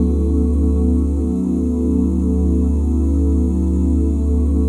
OOH DMAJ9.wav